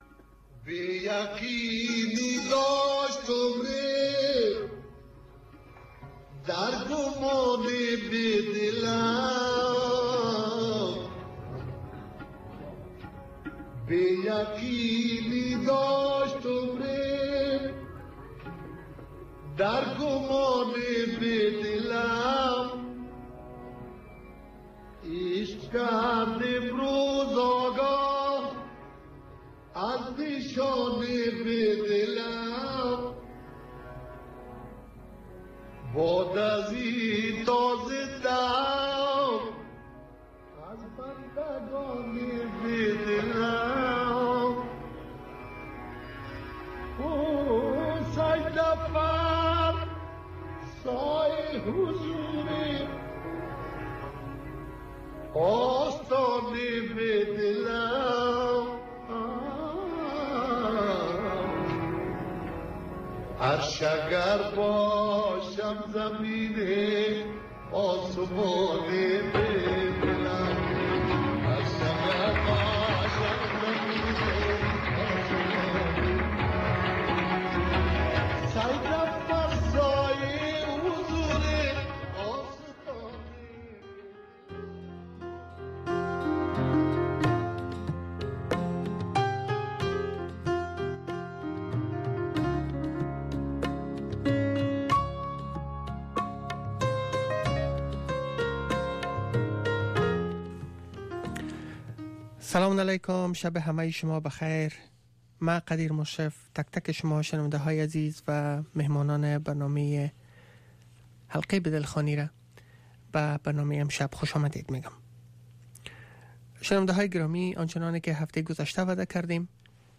The URL has been copied to your clipboard No media source currently available 0:00 0:38:41 0:00 لینک دانلود | ام‌پی ۳ برای شنیدن مصاحبه در صفحۀ جداگانه اینجا کلیک کنید